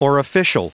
Prononciation du mot orificial en anglais (fichier audio)
Prononciation du mot : orificial